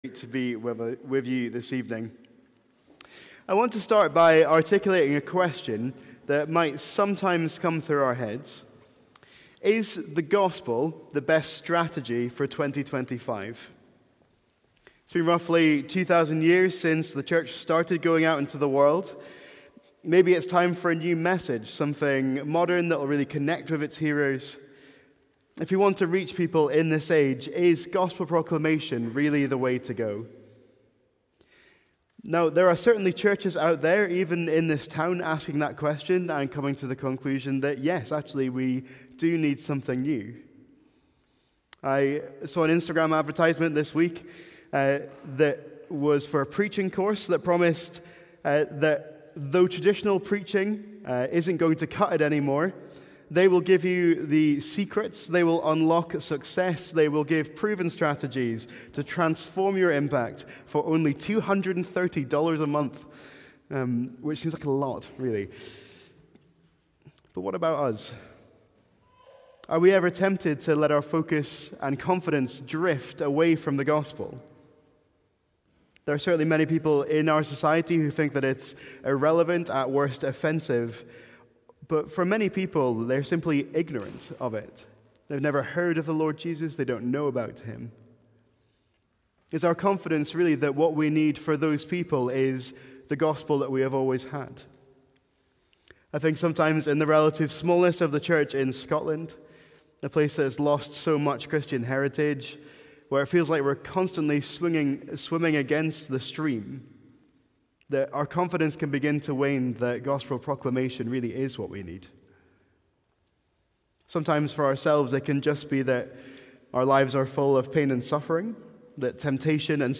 Sermons | St Andrews Free Church